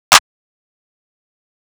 Metro clp.wav